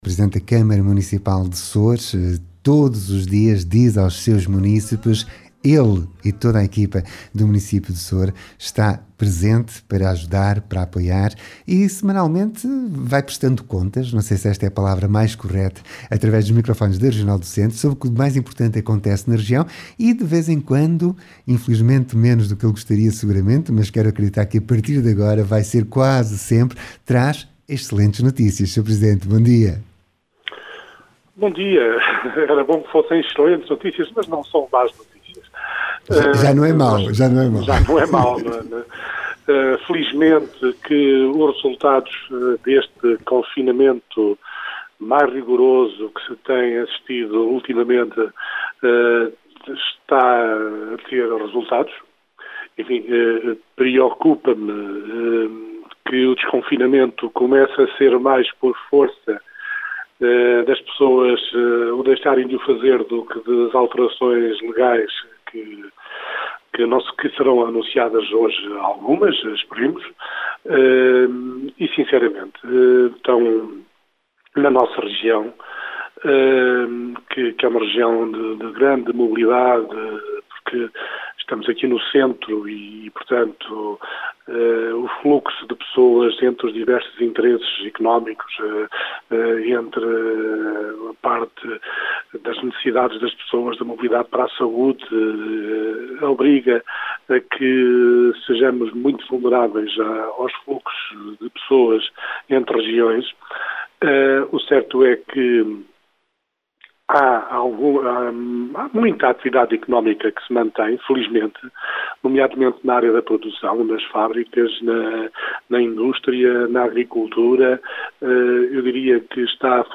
SOURE TEM VOZ – Hoje Mário Jorge Nunes, Presidente da Câmara Municipal de Soure, fala-nos da retoma da actividade cultural no concelho de Soure.